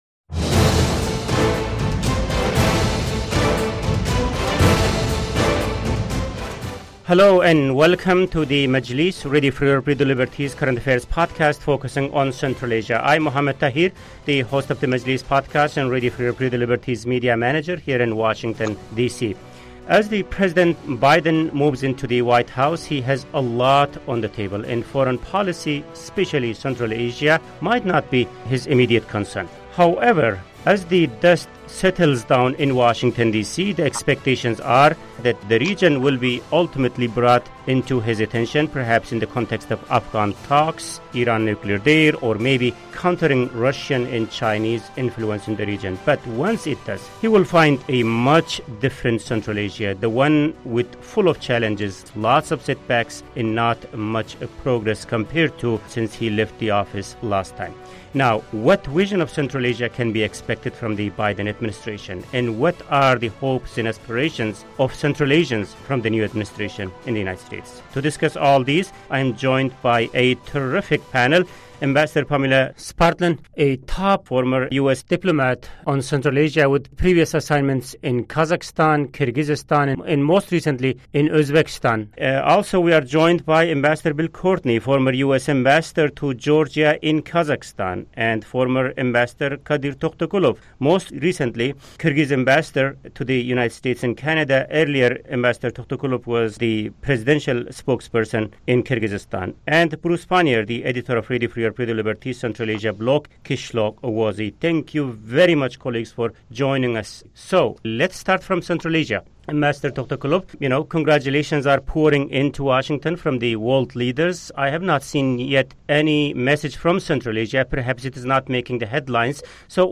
On this week's Majlis podcast, three former ambassadors share their thoughts about how the new Biden administration may change U.S. policy toward Central Asia.